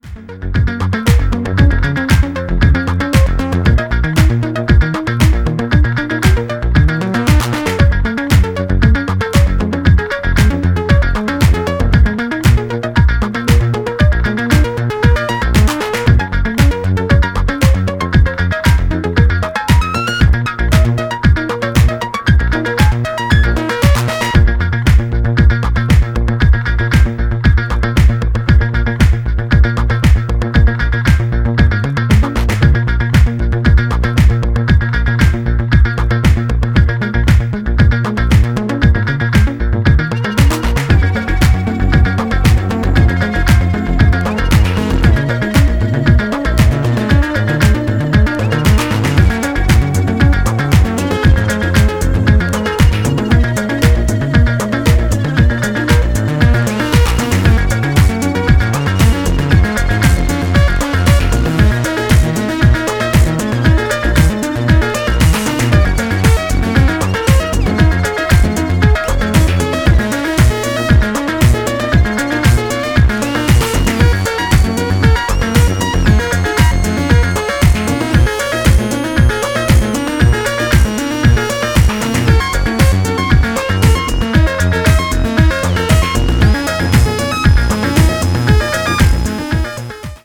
ジャンル(スタイル) DISCO HOUSE / DEEP HOUSE / BALEARIC HOUSE